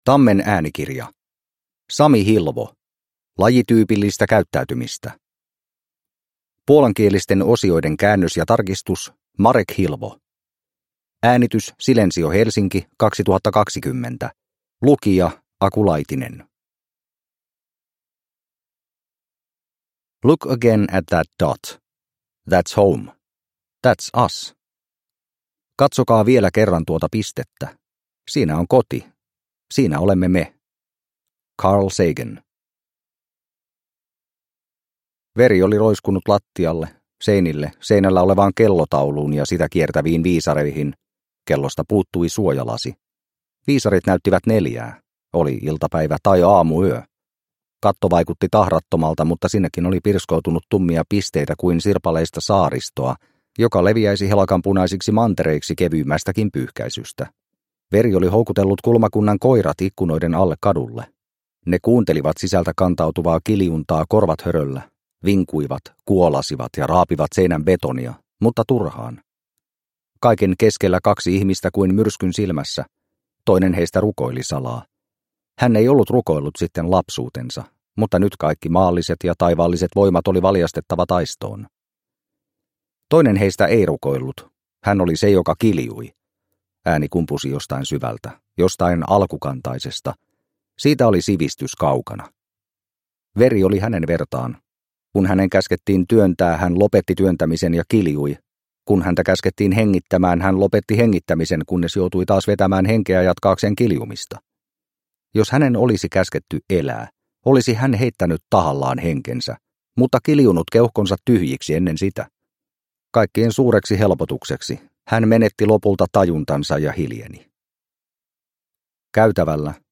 Lajityypillistä käyttäytymistä – Ljudbok – Laddas ner